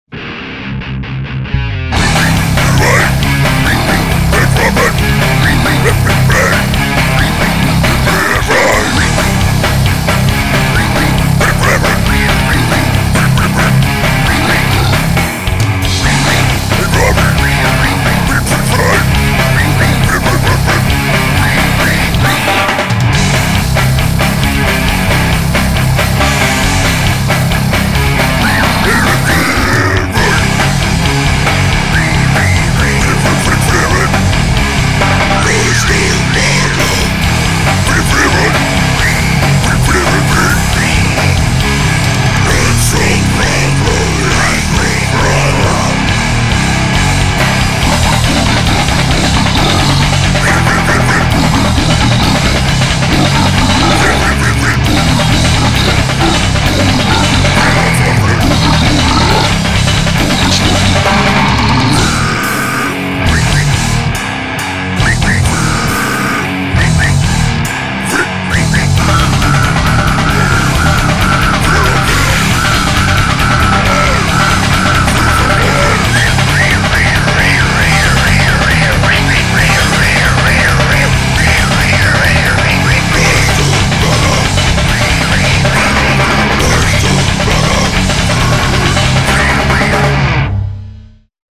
boost bass
guitar
drums
vocals